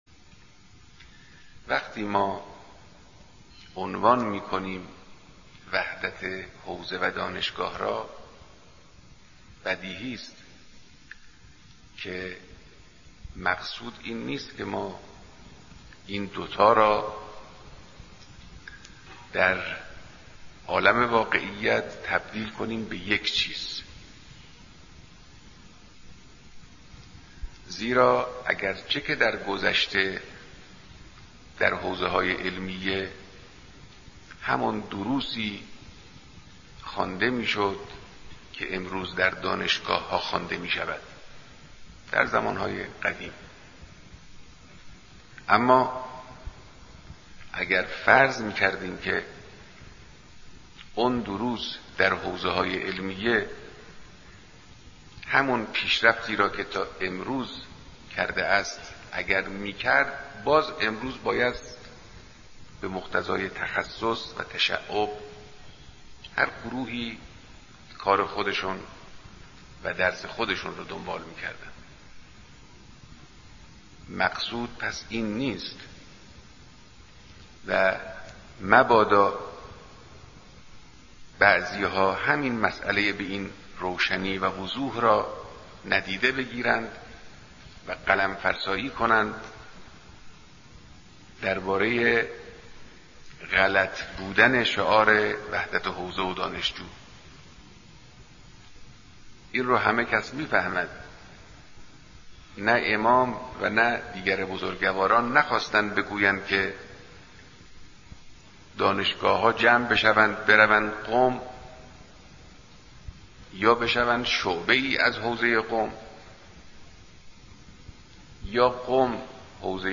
بيانات درديدار با دانشجويان به مناسبت روز وحدت حوزه و دانشگاه